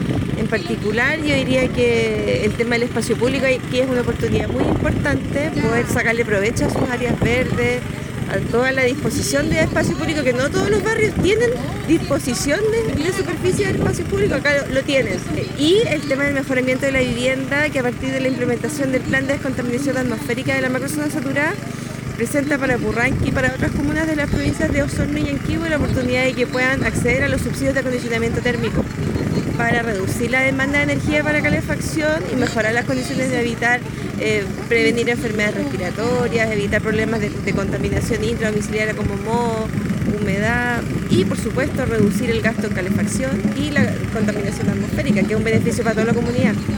En el acto oficial que dio la bienvenida al Programa, se contó con masiva participación vecinal, quienes escucharon el discurso de autoridades locales y regionales.